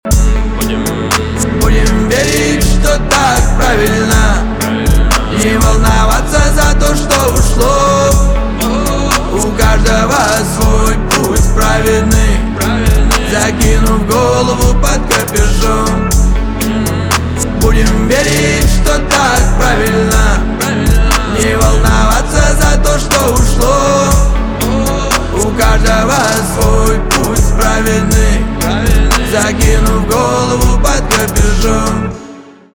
русский рэп
битовые , басы